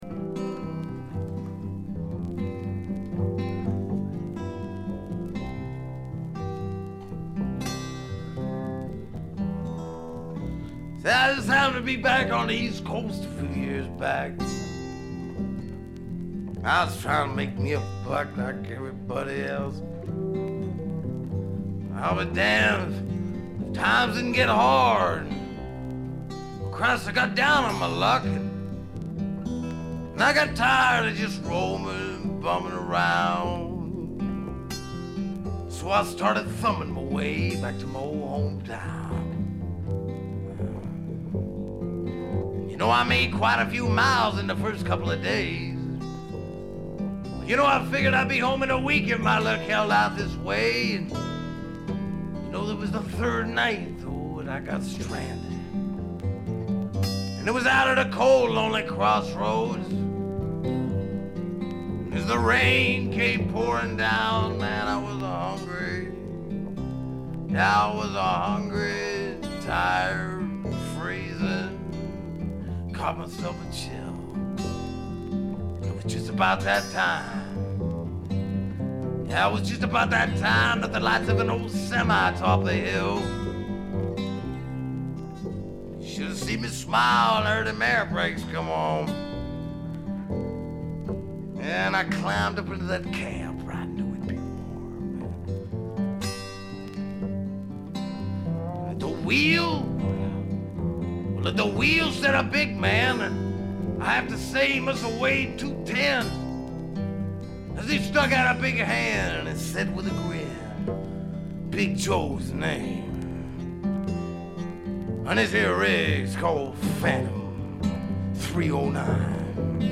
部分試聴ですがわずかなノイズ感のみ。
70年代シンガー・ソングライターの時代を代表するライヴアルバムでもあります。
試聴曲は現品からの取り込み音源です。